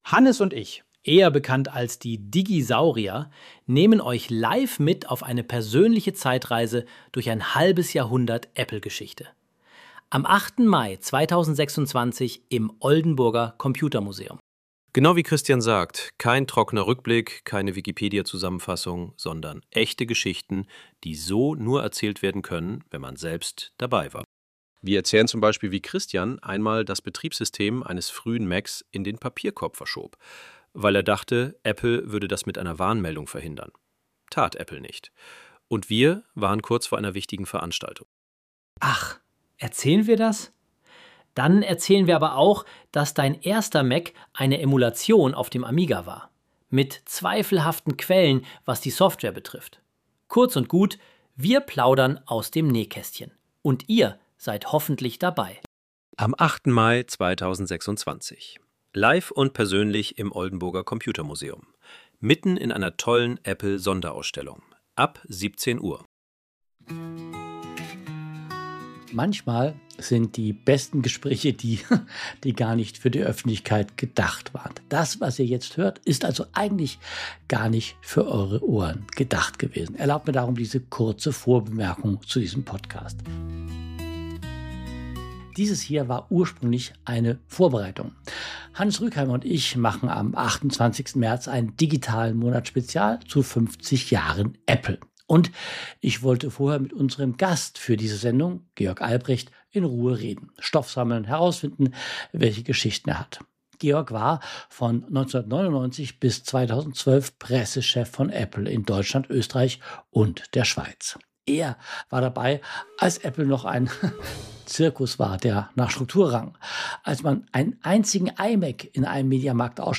Kein geglättetes Interview.
Sondern ein echtes Gespräch – mit Umwegen, Lachen und Erinnerungen, die sich gegenseitig ergänzen. Ein Sonder-Podcast und Erinnerungs-Special der besonderen Art.